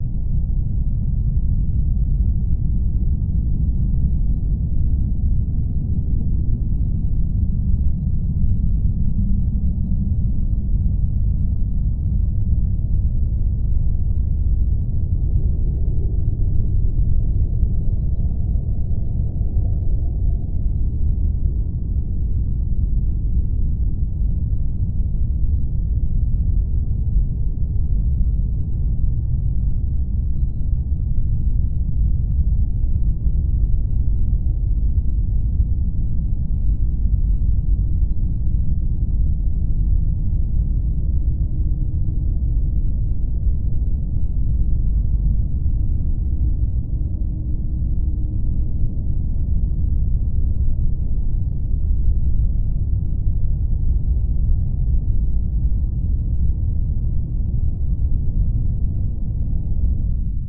На этой странице собраны звуки пустоты – завораживающие, мистические и медитативные аудиозаписи.
Звук пустоты под землей